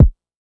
Kicks
MetroKick.wav